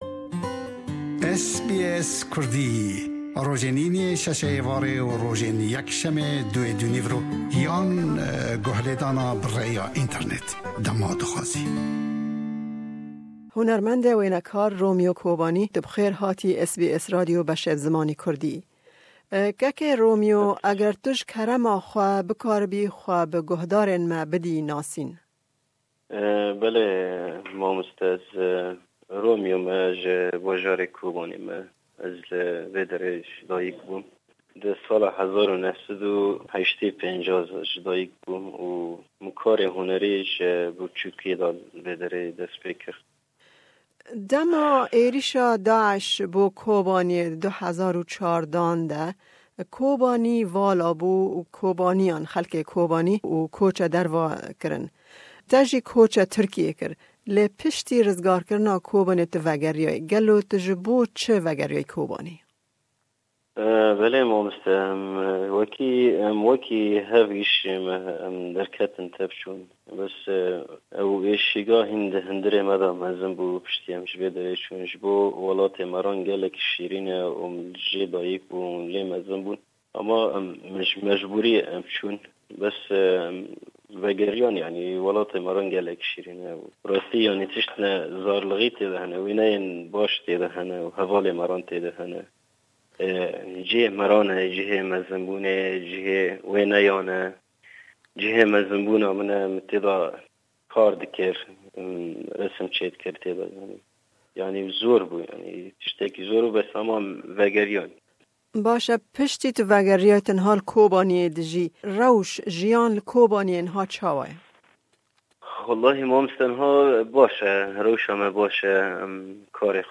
Ew di hevpeyvînê de behsa dijwariyên dema xelkê Kobaniyê di 2014 de dema Daish êrish kir dike. Ew herweha li ser evîniya xwe bo karê hunera shêwekariyê jî dike.